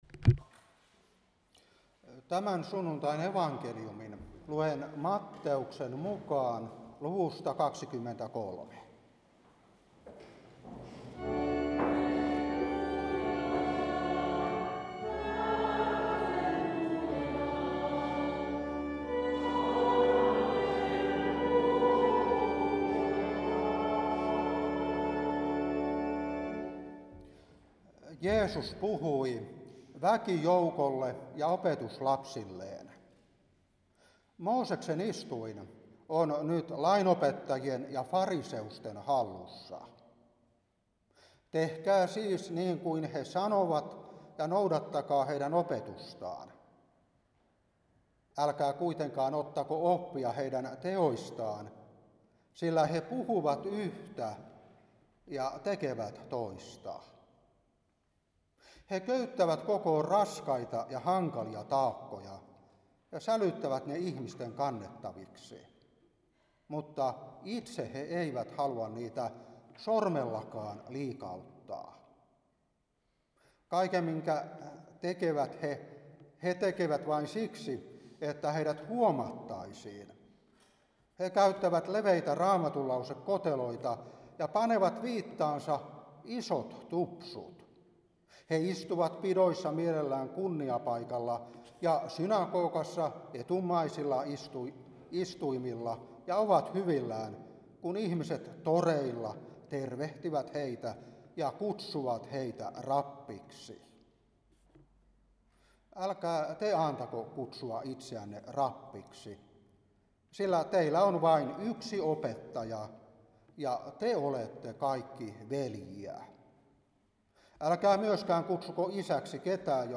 Saarna 2003-8.